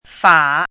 汉字“法”的拼音是：fǎ。
“法”读音
法字注音：ㄈㄚˇ
国际音标：fɑ˨˩˦
fǎ.mp3